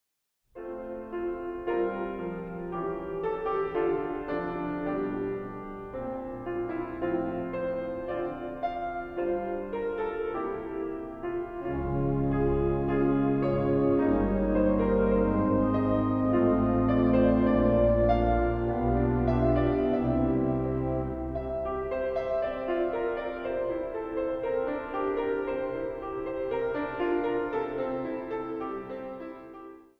orgel
piano
tenor.
Zang | Mannenkoor